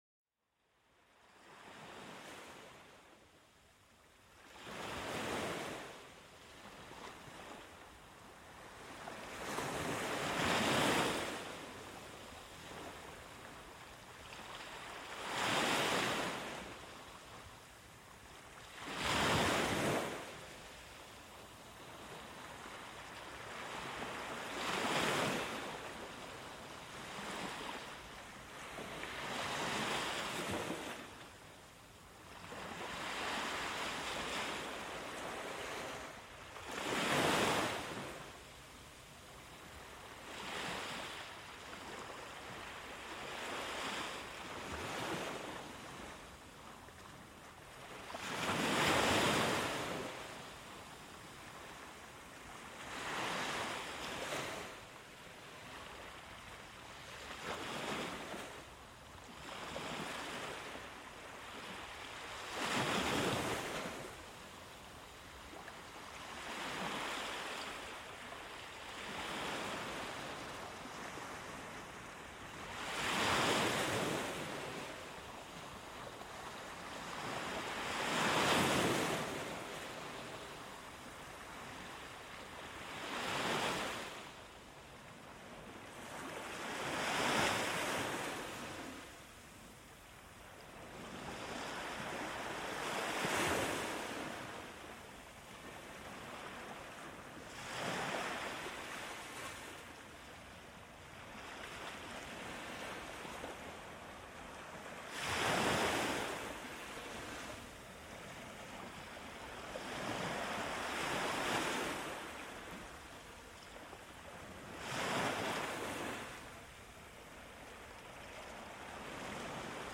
Sumérgete en el ambiente relajante de las olas del océano y déjate mecer por su ritmo constante. Este episodio te invita a una escapada sónica donde cada ola te acerca más a una tranquilidad profunda. Ideal para relajarte al final del día o acompañar tus momentos de meditación.Este podcast ofrece una selección rica de sonidos naturales, perfectos para la relajación y el sueño.